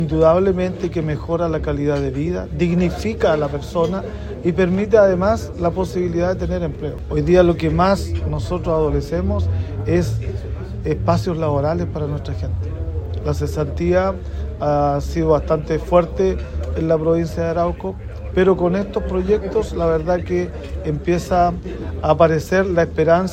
El alcalde de Cañete, Jorge Radonich, dijo que con esta inversión, además de mejorar la calidad de vida de los vecinos, se generarán empleos.
alcalde-canete.mp3